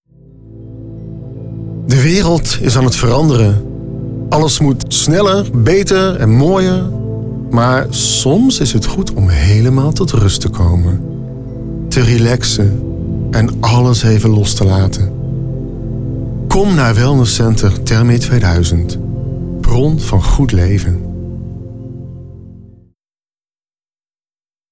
Een aantal voorbeelden van mijn voice-over opdrachten